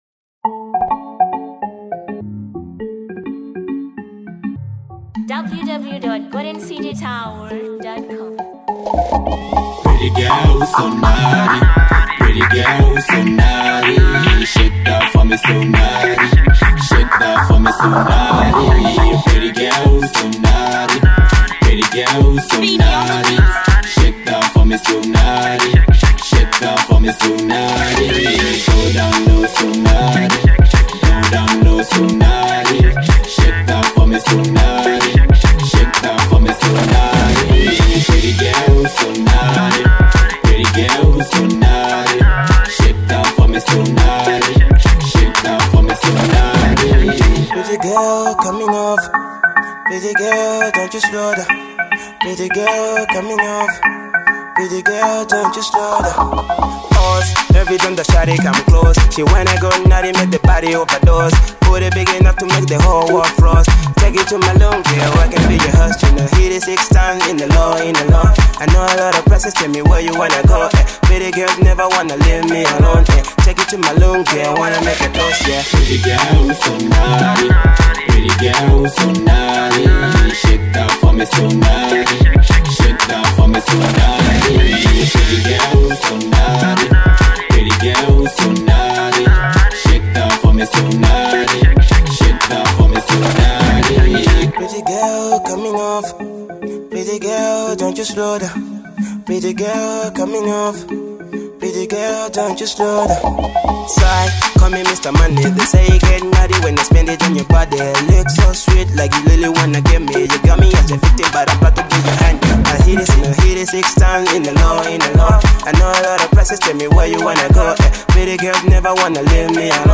2. Afro Pop